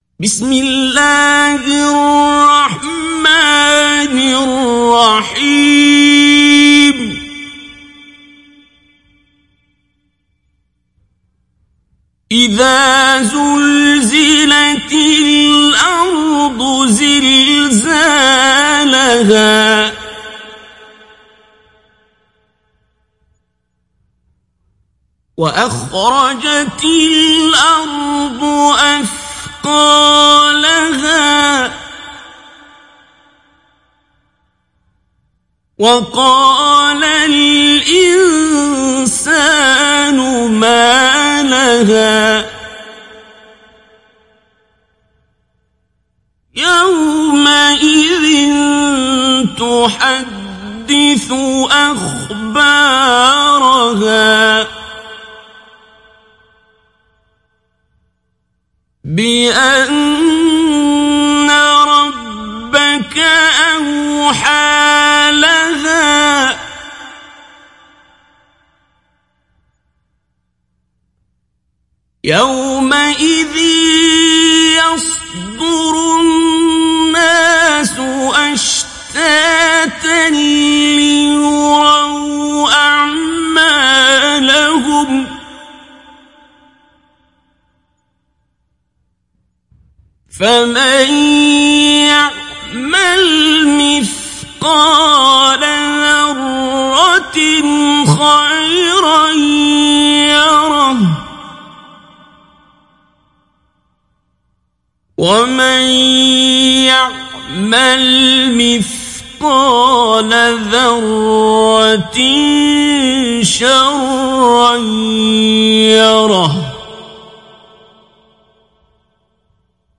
تحميل سورة الزلزلة عبد الباسط عبد الصمد مجود